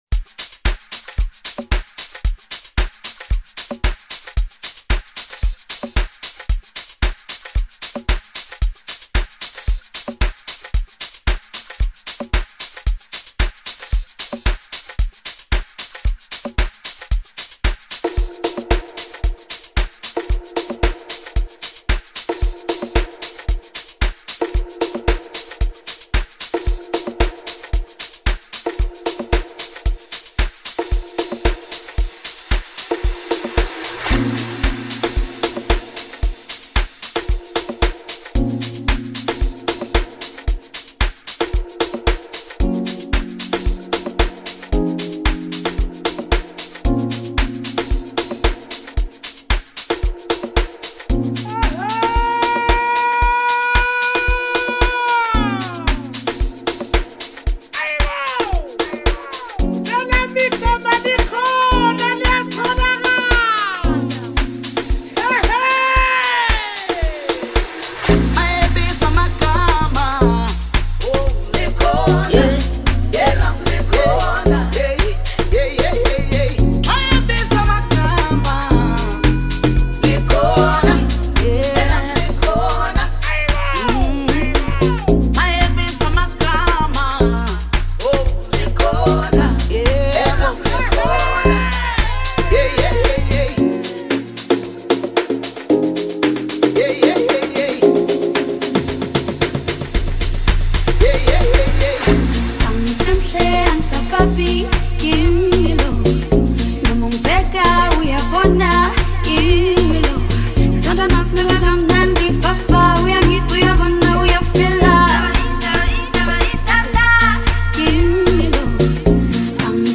South African Music.